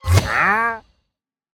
Minecraft Version Minecraft Version 25w18a Latest Release | Latest Snapshot 25w18a / assets / minecraft / sounds / mob / wandering_trader / disappeared2.ogg Compare With Compare With Latest Release | Latest Snapshot
disappeared2.ogg